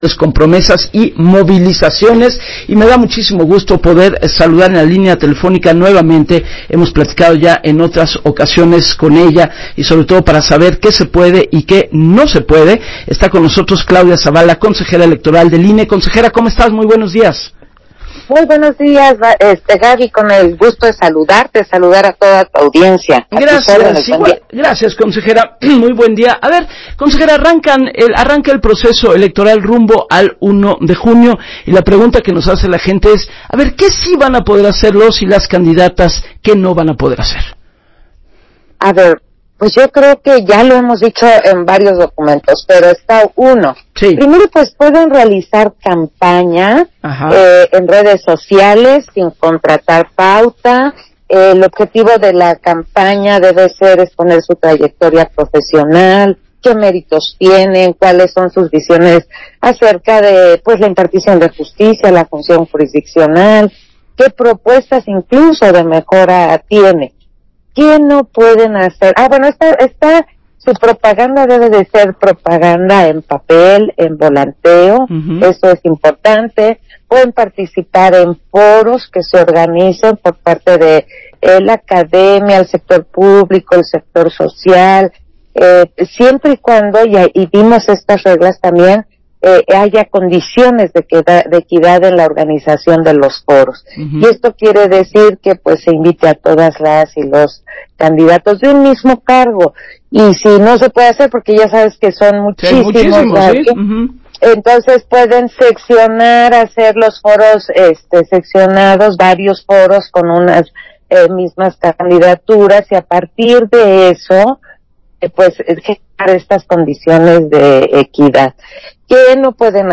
Entrevista de la Consejera Electoral Claudia Zavala con Gabriela Warkentin para W Radio